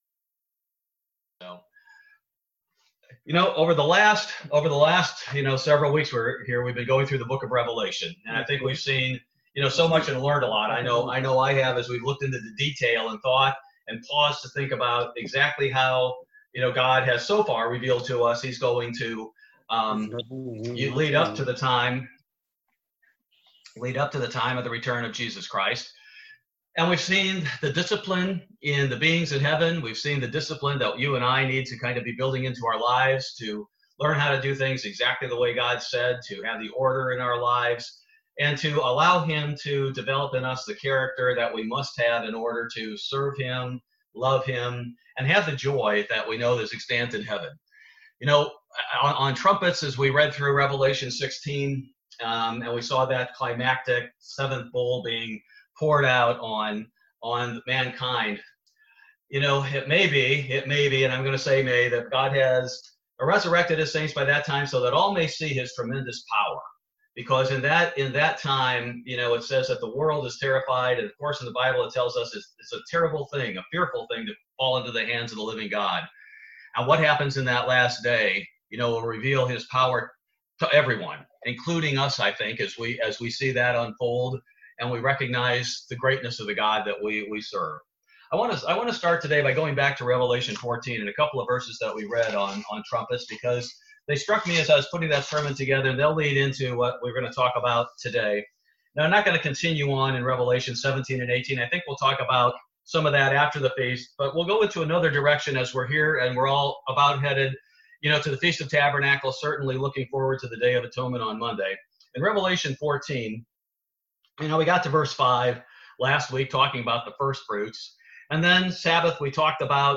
Bible Study - September 23, 2020